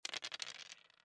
dice1.mp3